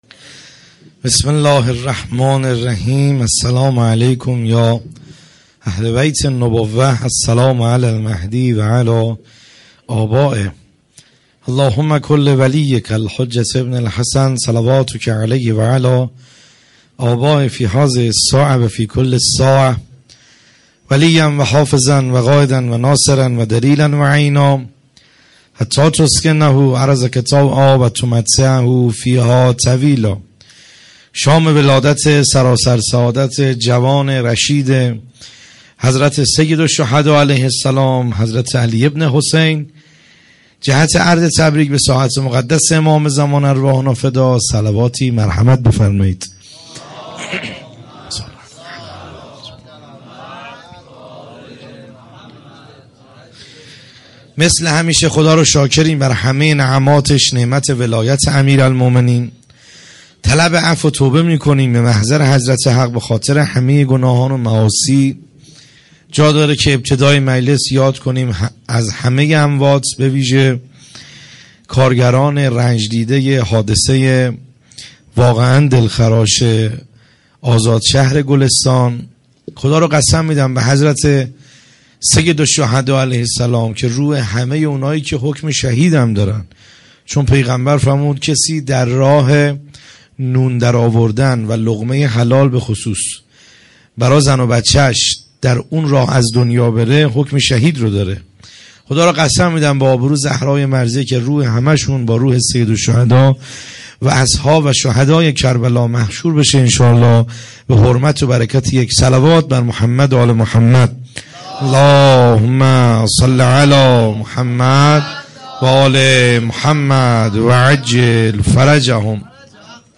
خیمه گاه - بیرق معظم محبین حضرت صاحب الزمان(عج) - سخنرانی | کیفیت عالی